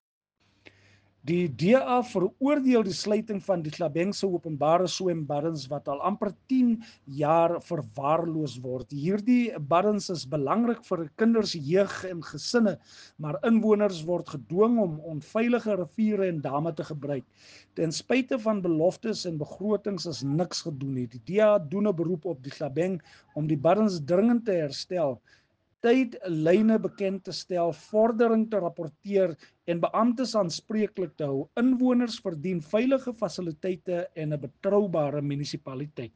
Afrikaans soundbites by Cllr Marius Marais and